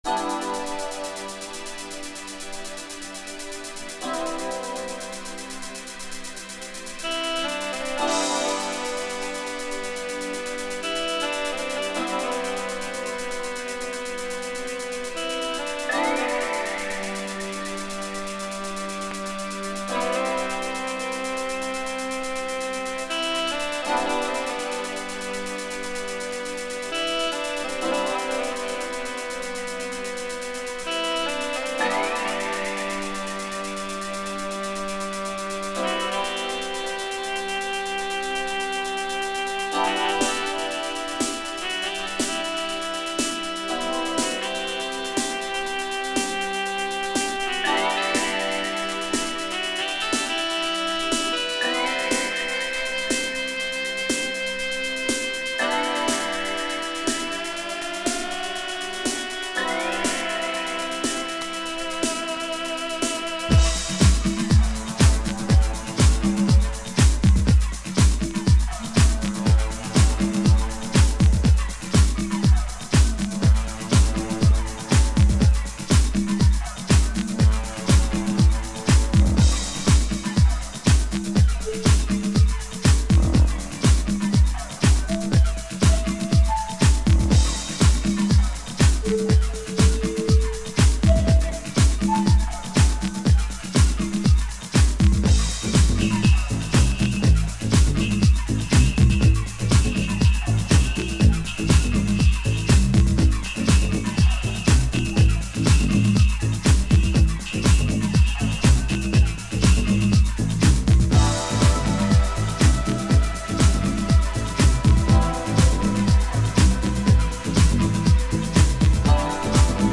House Mix
Instrumental